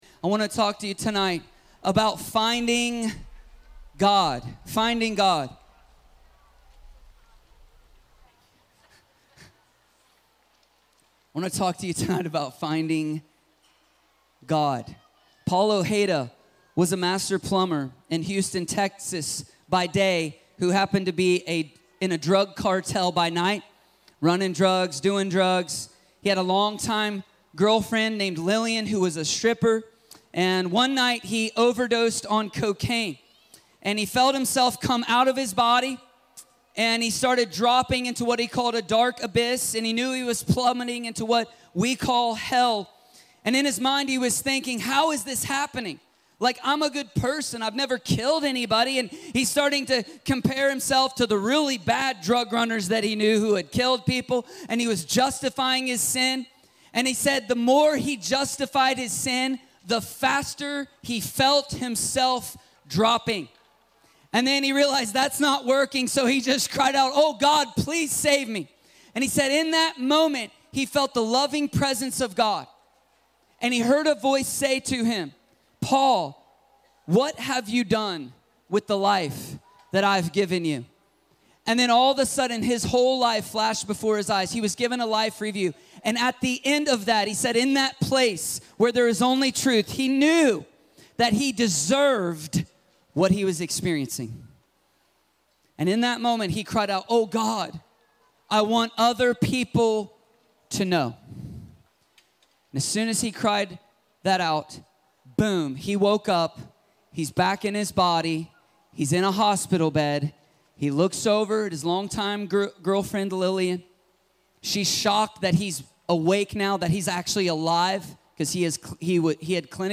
Finding God - Stand Alone Messages ~ Free People Church: AUDIO Sermons Podcast